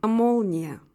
La syllabe accentuée a une prononciation plus longue et plus appuyée que les autres.
молния [i][9]